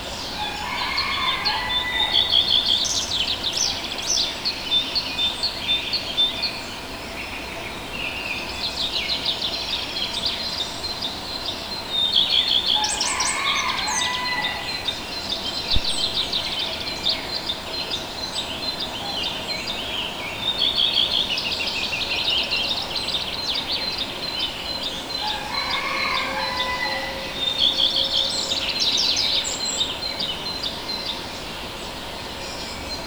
• early mountain birds habitat 7.wav
Great place to record the natural habitat of birds and animals in the beautiful Southern Carpathian Mountains. Recorded with Tascam DR 40